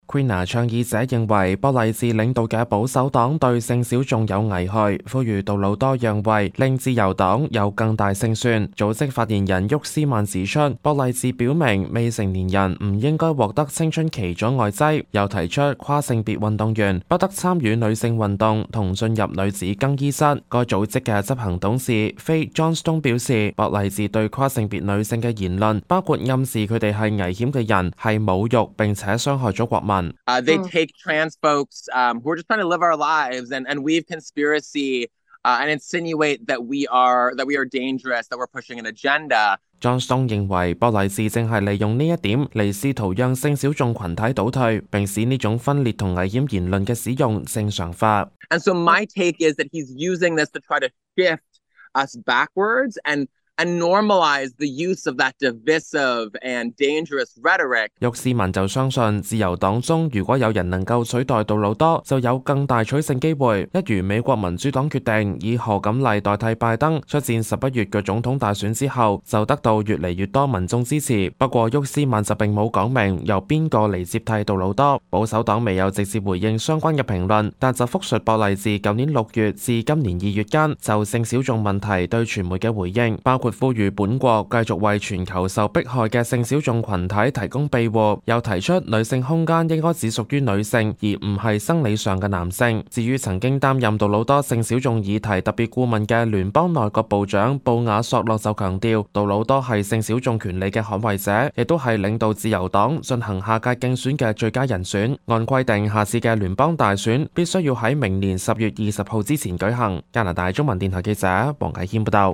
news_clip_20430.mp3